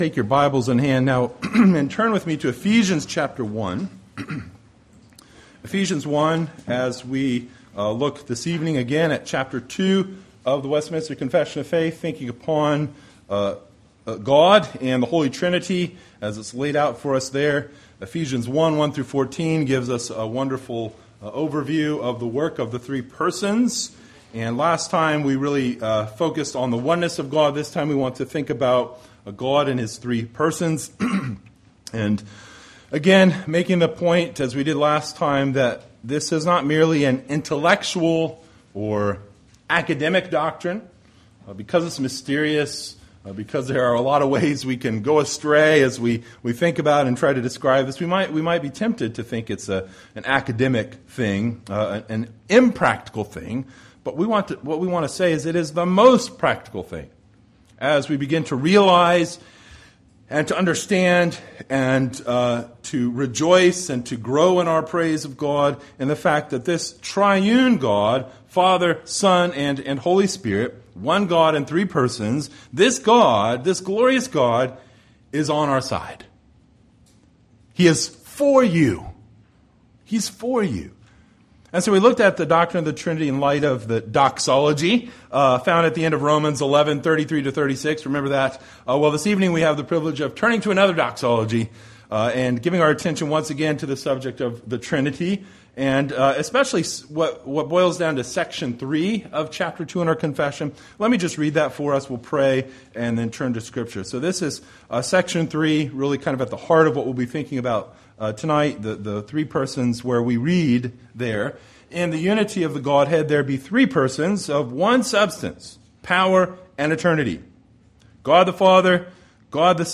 Ephesians 1:1-14 Service Type: Sunday Evening Related « Solid Food Falling Away »